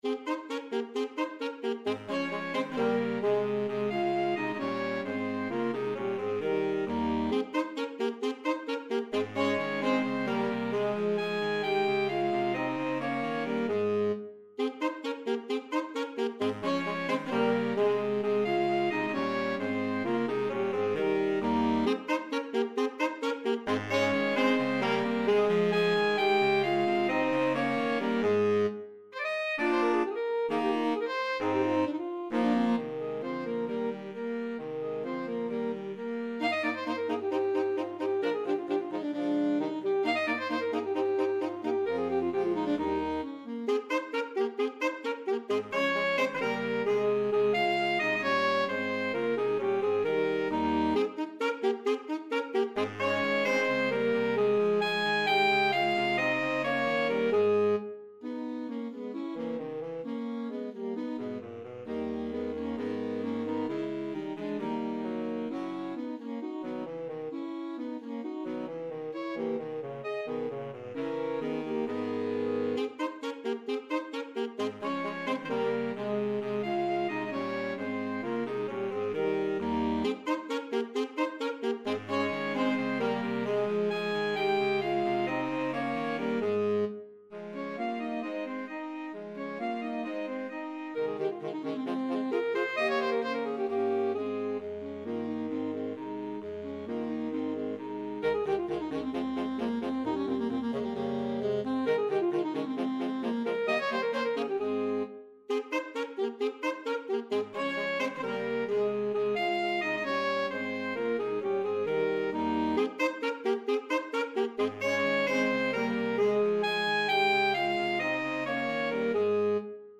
Voicing: Saxophone Quartet